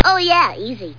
ohyeah.mp3